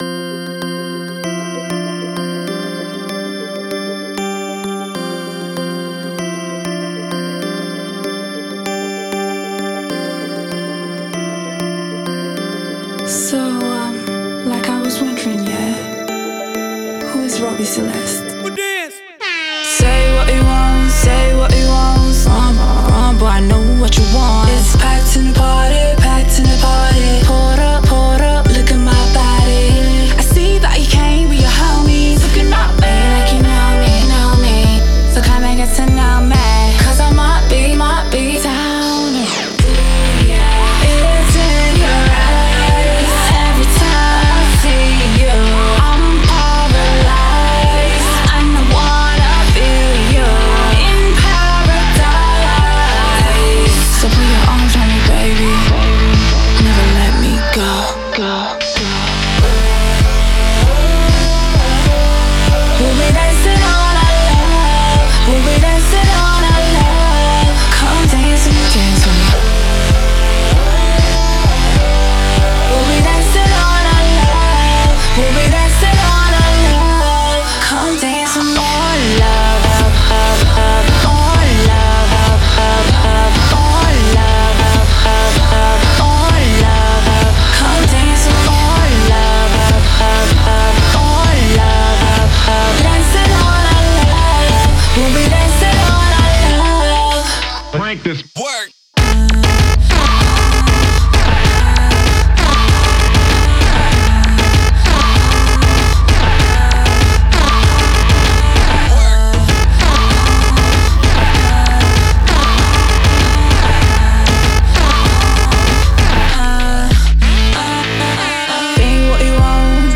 the Afr0-Dubstep/Pop tune